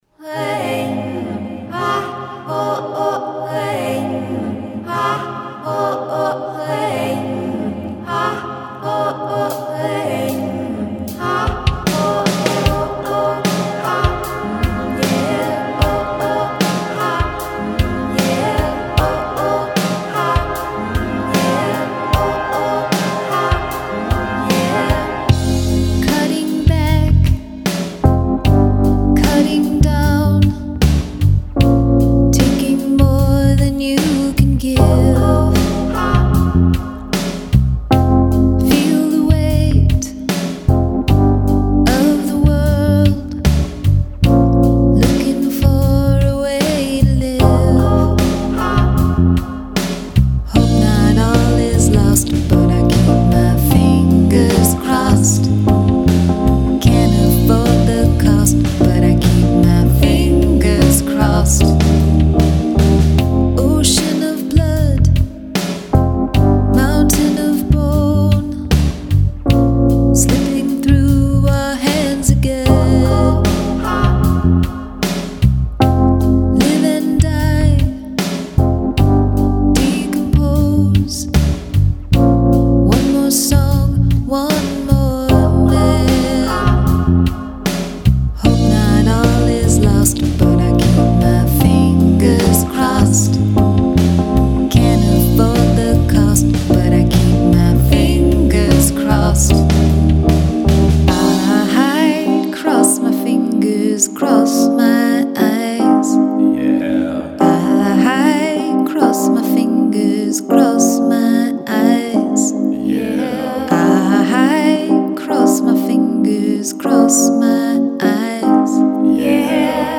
include a section of backwards singing or instrumentation
Heavenly vocals.